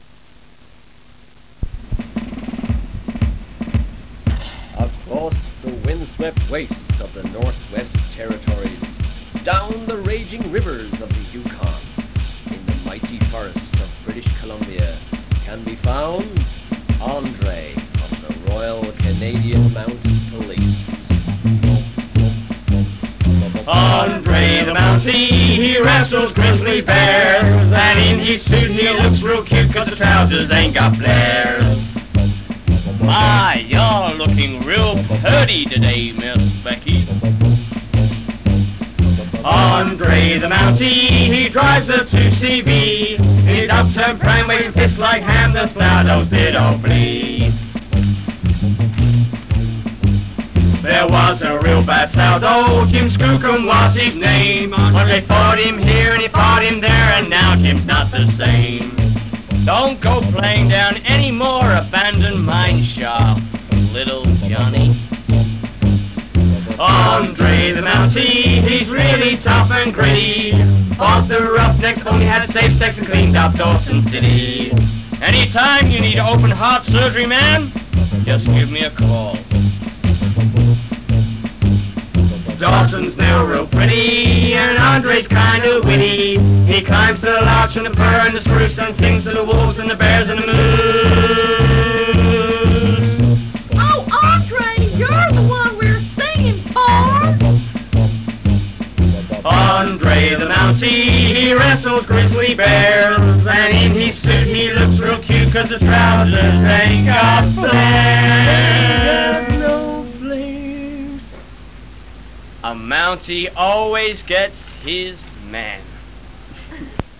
The song was cut on the third take. If you've ever wondered what four drunks singing in a converted pig shed sound like, you can listen to this sound file...
A minimum of 4 people and a pianist is needed to perform this ballad...
(Ballad starts with a Canadian narrator)